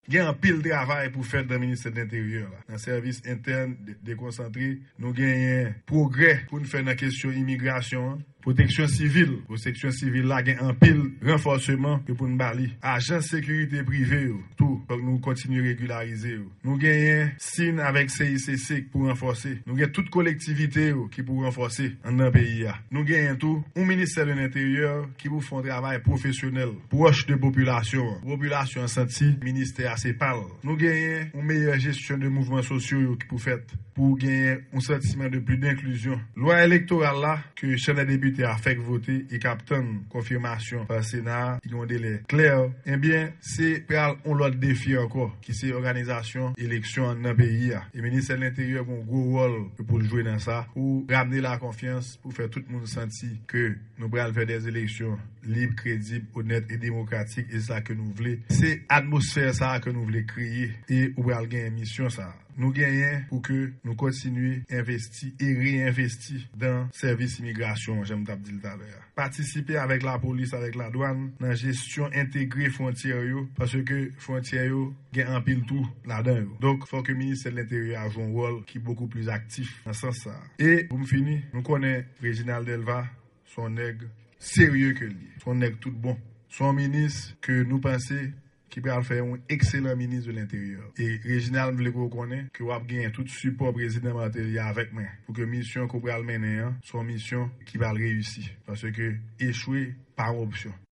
Deklarasyon Premye Minis Lamothe ak Réginald Delva nan Enstalasyon nouvo minis enteryè a